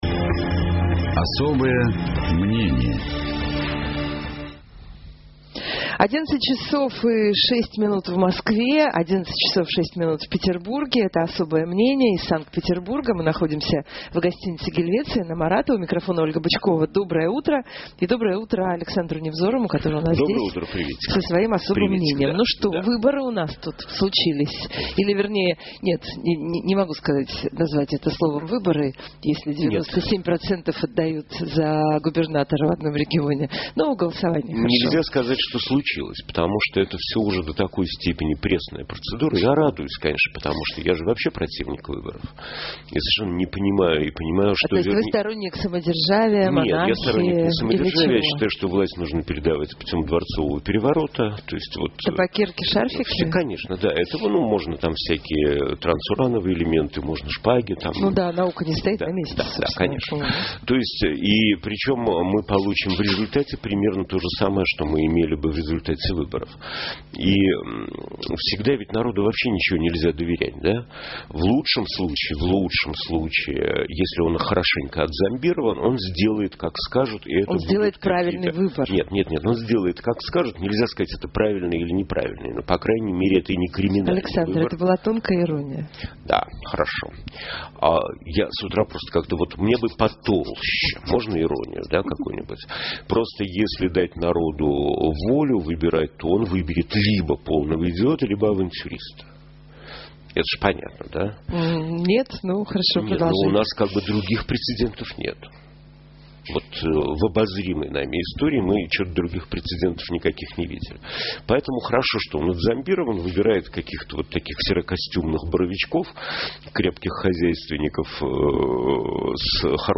Мы находимся в гостинице «Гельвеция».